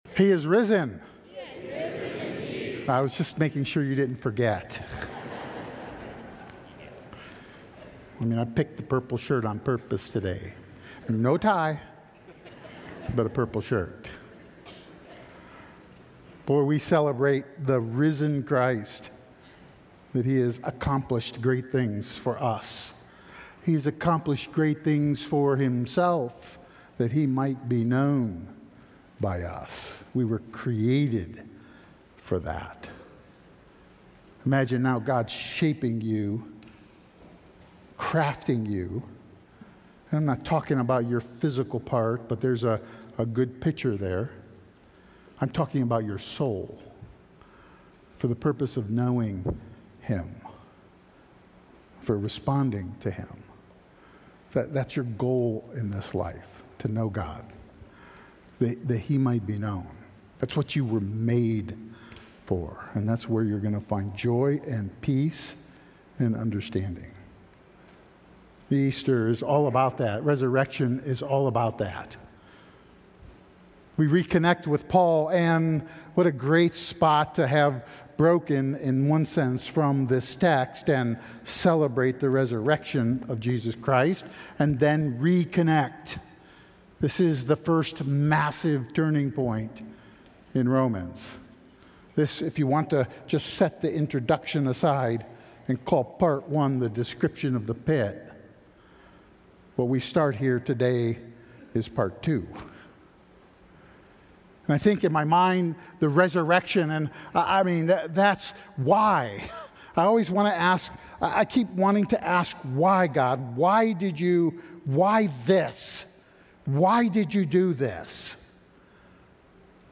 The Power of God Audio File Sermon Notes More From This Series Farewell...